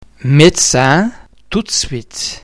the [d] under the influence of [c] [s] [f] and [t] is pronounced [t]